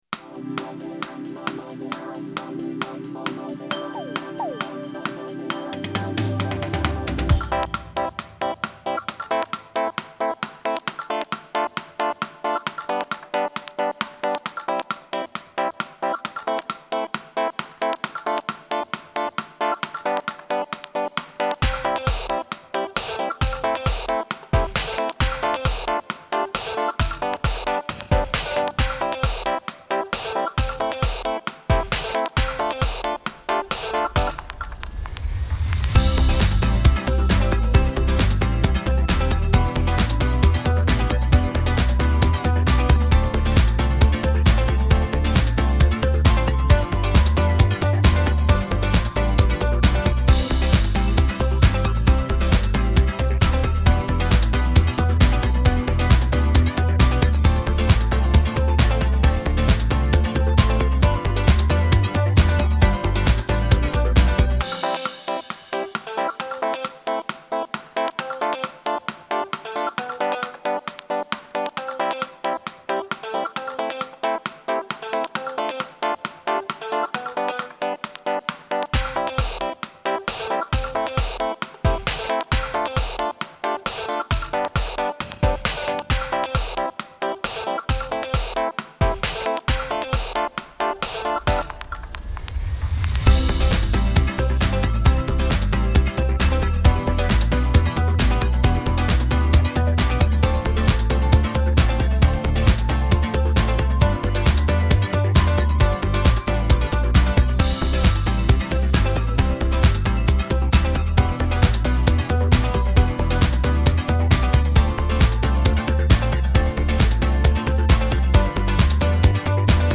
караоке
Скачать минус детской песни